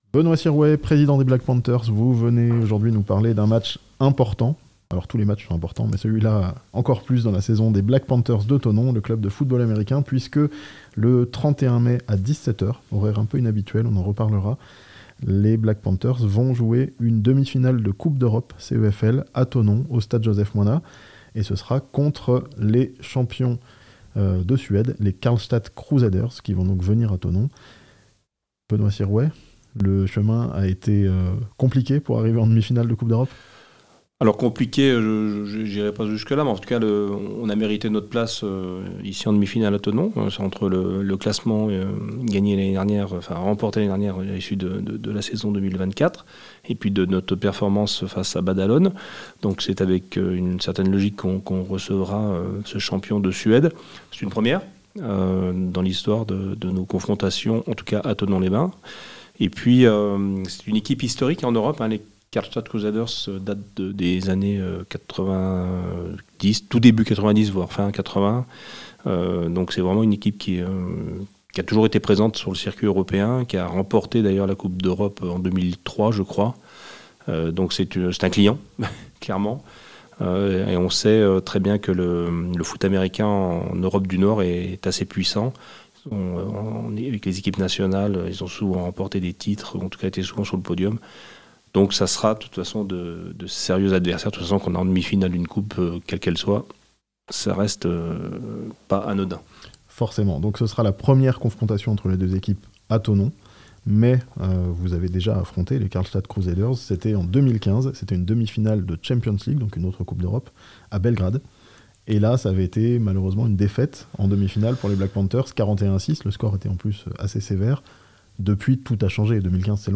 Une demi-finale de coupe d'Europe à Thonon le 31 mai (interview)